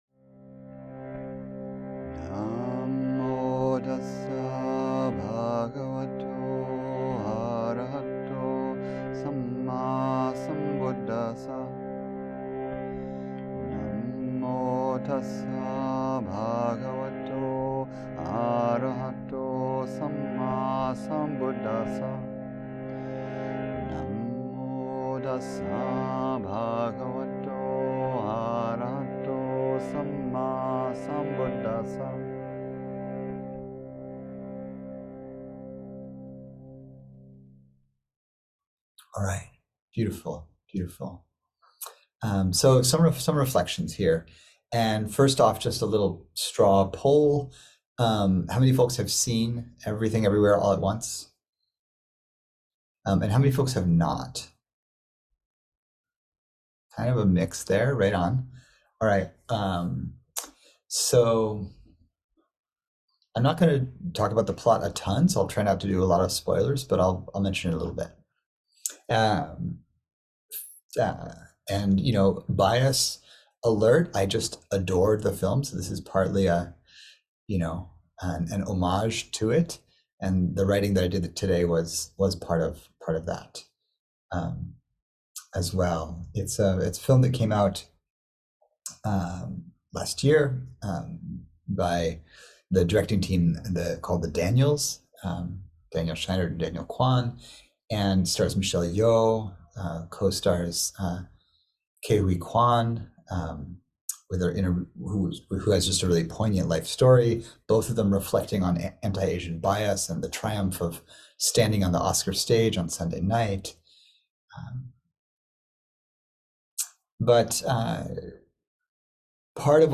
Recorded at Insight Meditation Satsang